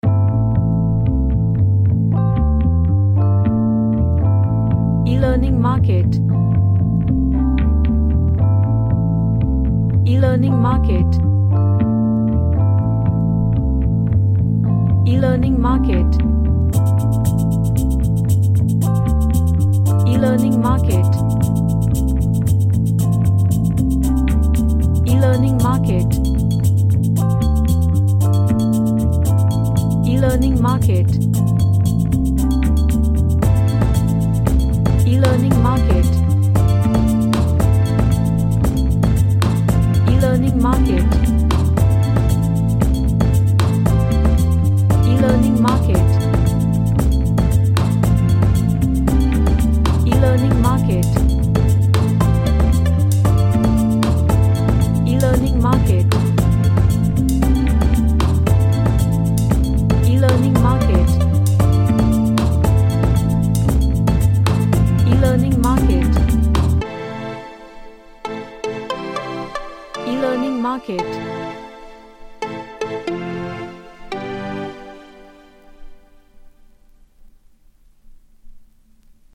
A latin electronic track
Gentle / Light